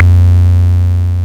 BUCHLA.F3  2.wav